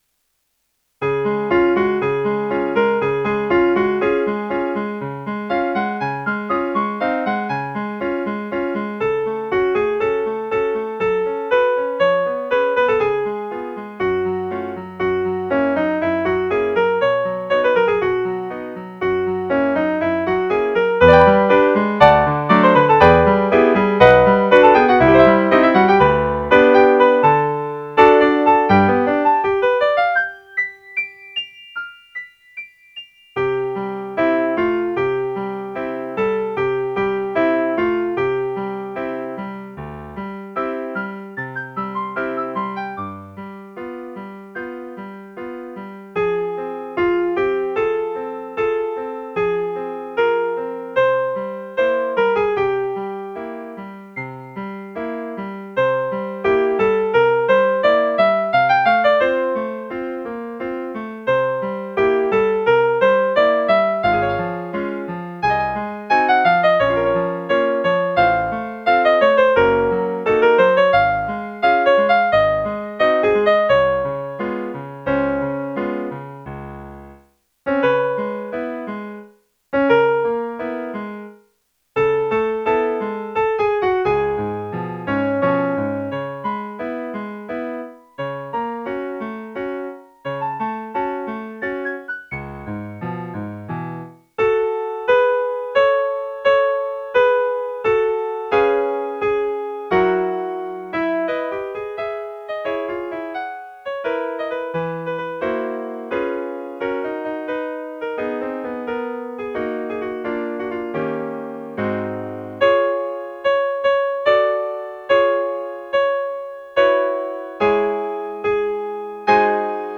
''Neig o schone Knospe'' - 피아노 반주